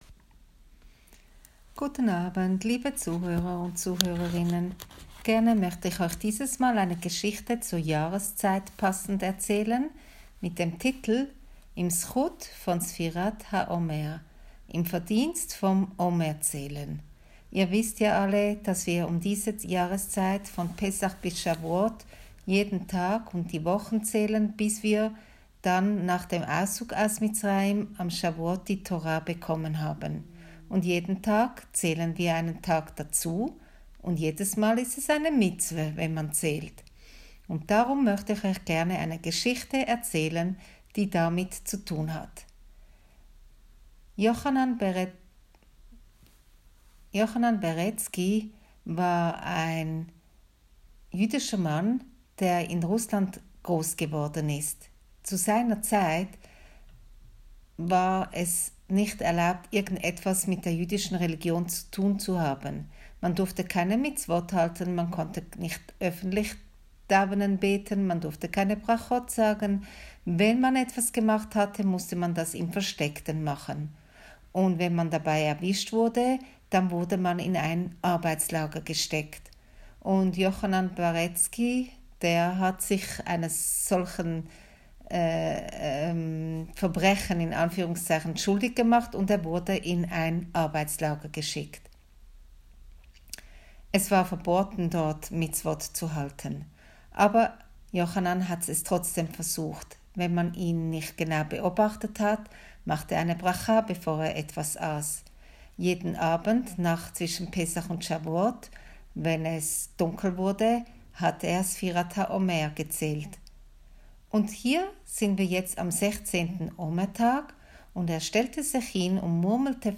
Heute möchte ich euch eine Geschichte passend zur Jahreszeit erzählen mit dem Titel: